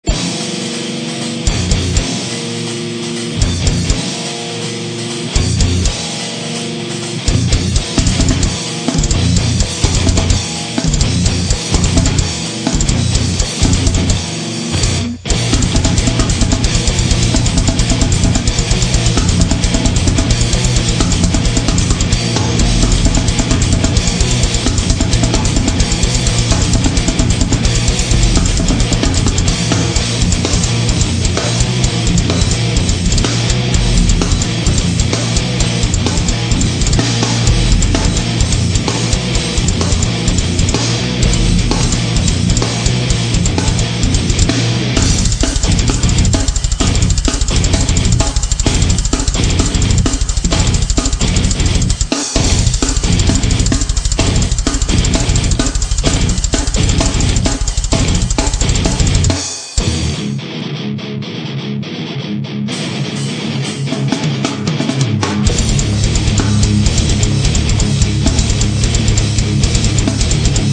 Recorded at Music Lab Studios, Austin, Tx.